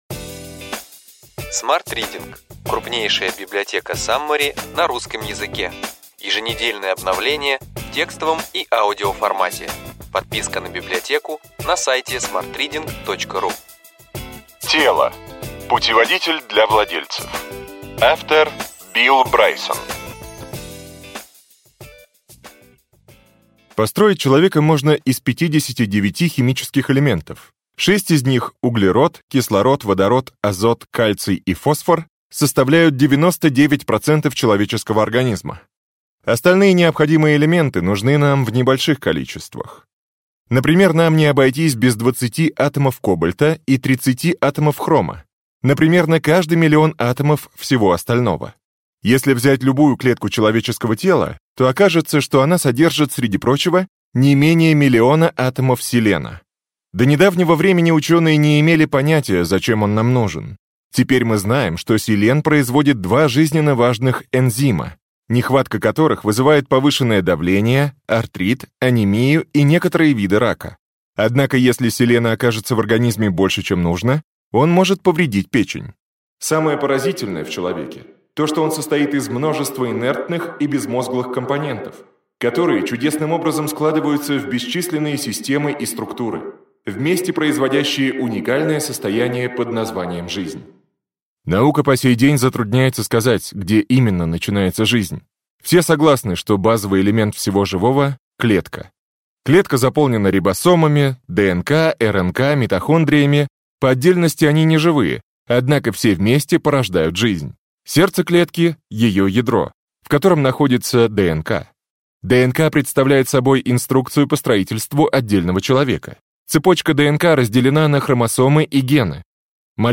Аудиокнига Ключевые идеи книги: Тело. Путеводитель для владельцев. Билл Брайсон | Библиотека аудиокниг